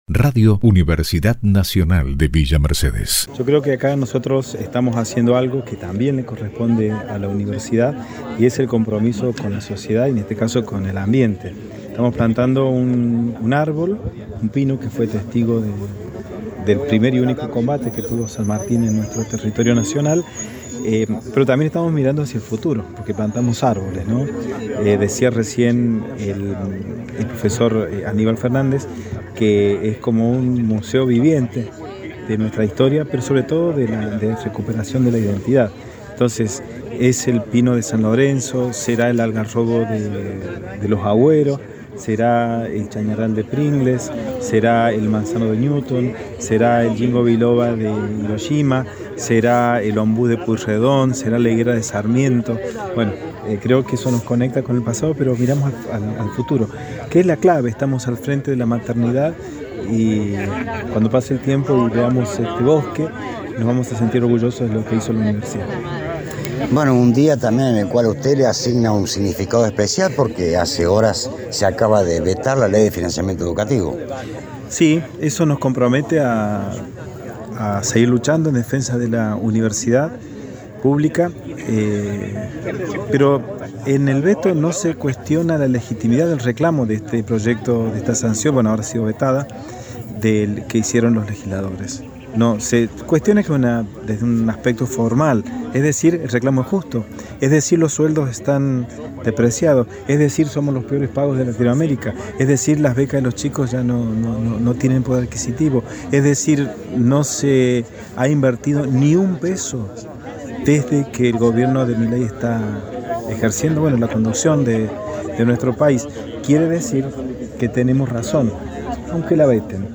Al término del acto, Prensa Institucional recabó la opinión del Rector Marcelo Sosa
Rector de la UNViMe - Dr. Marcelo Sosa